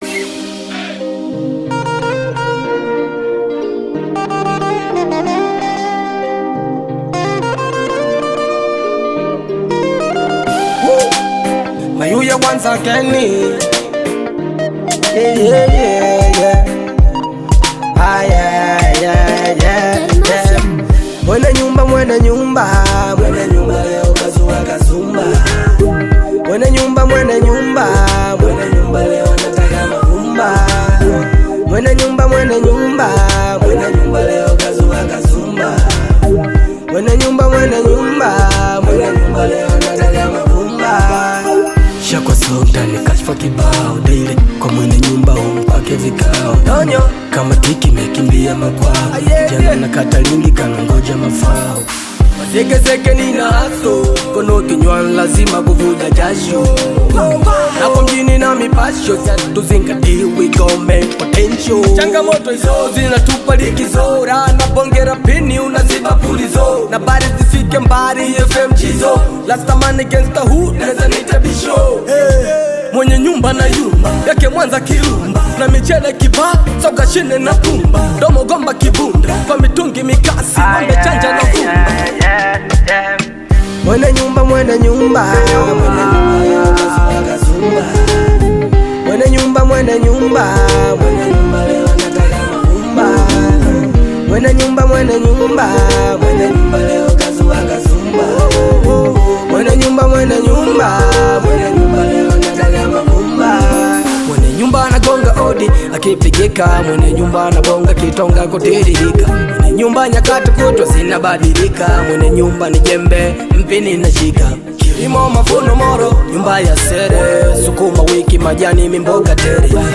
emotionally charged
With its infectious beat and captivating vocals